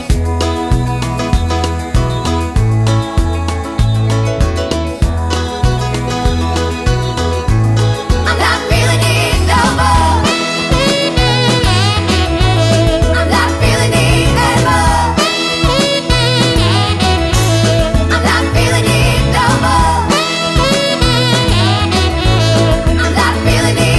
2 verses cut out Pop (1960s) 4:05 Buy £1.50